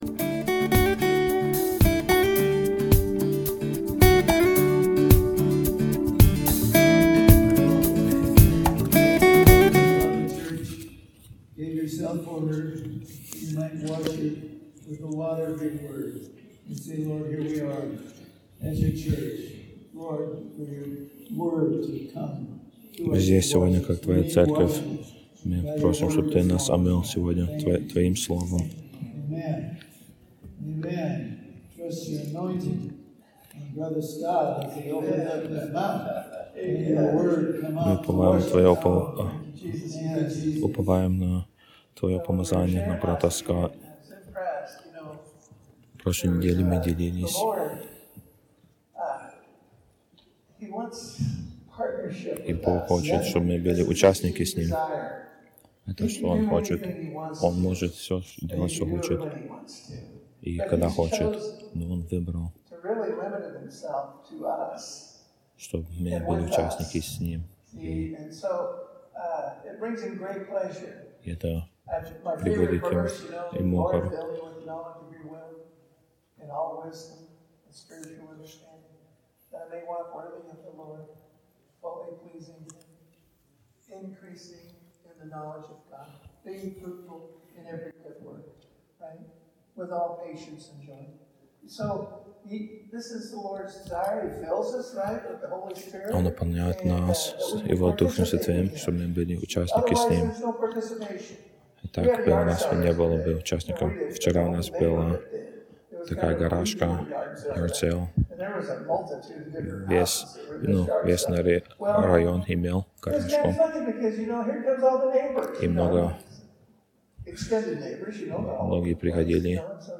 Русские проповеди